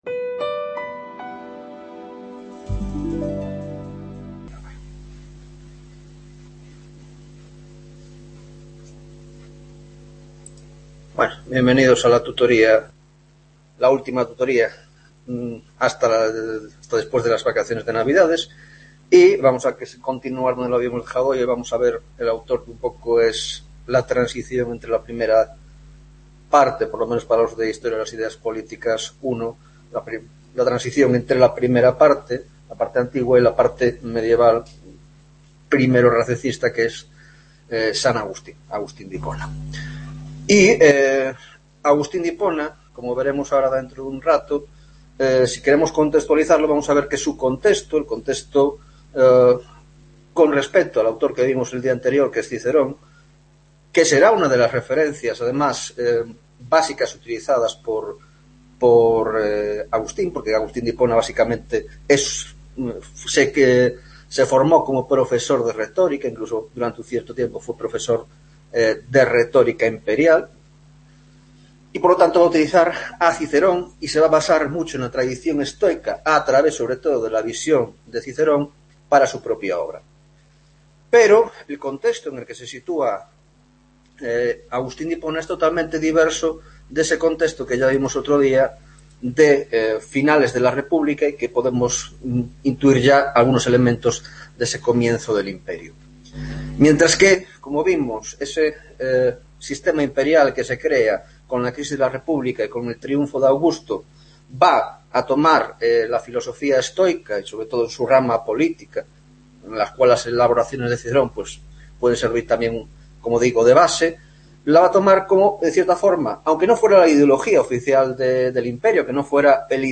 8ª Tutoria de Historia de las Ideas Políticas (Grado de Ciencias Políticas y Grado de Sociologia) - Agustin de Hipona